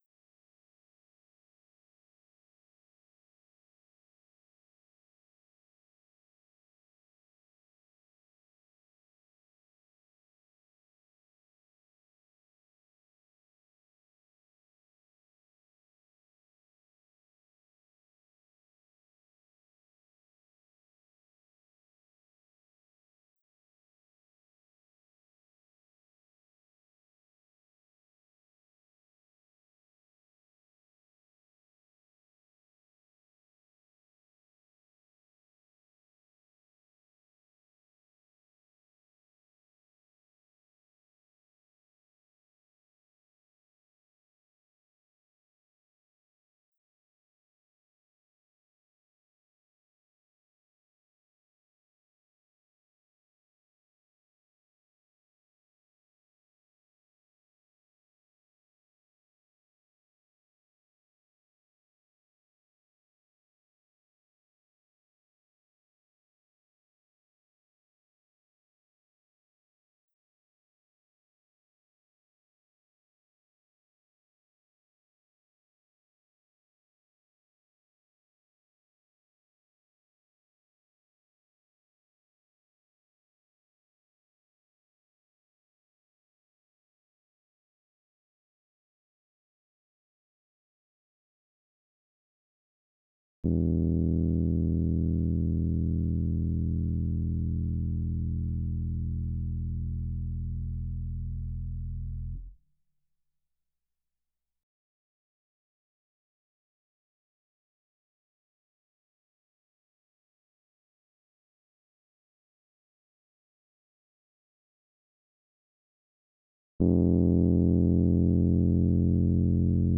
These are the reverb Chicago Electric Piano co samples, de-glitched and prepared as slices for the m8